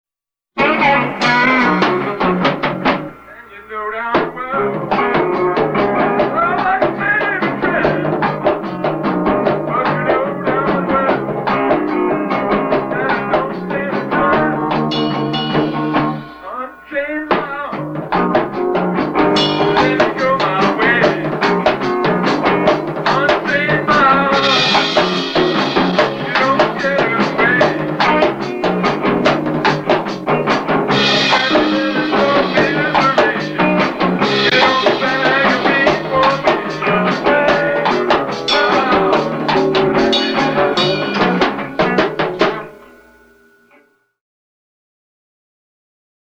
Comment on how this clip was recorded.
Practice Tape 1-86